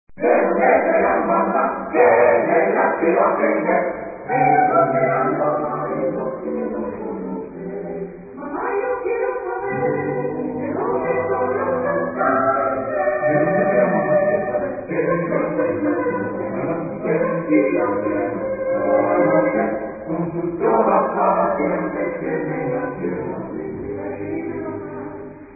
SATB (4 voces Coro mixto).